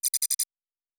pgs/Assets/Audio/Sci-Fi Sounds/Interface/Data 14.wav